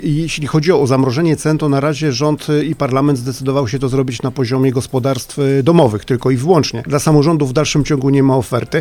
Zapowiadana specustawa nie została jednak do tej pory skierowana do Sejmu. Prezydent Radomia dodaje: